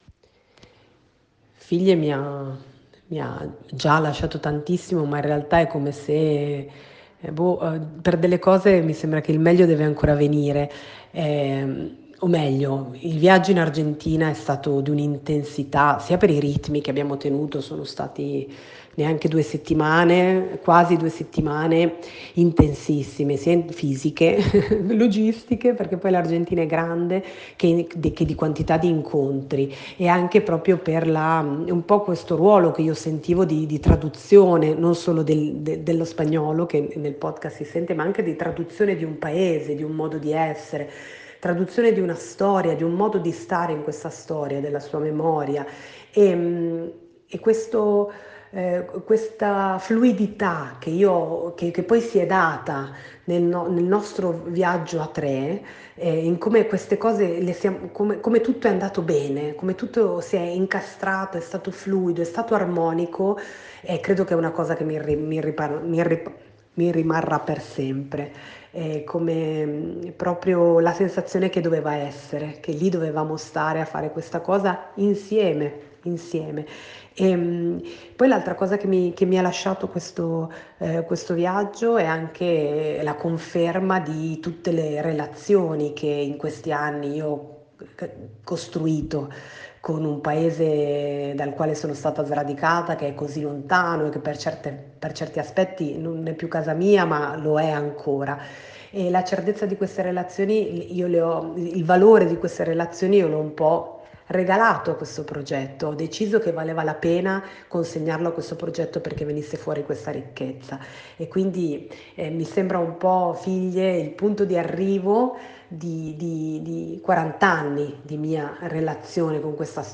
audio-intervista